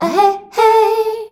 AHEHEY  F.wav